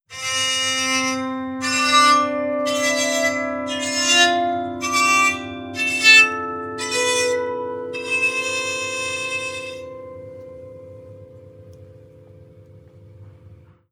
Psaltery Free sound effects and audio clips
Original creative-commons licensed sounds for DJ's and music producers, recorded with high quality studio microphones.
soprano diatonic psaltery scales.wav
soprano_diatonic_psaltery_scales_nOt.wav